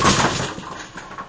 bowling-4.wav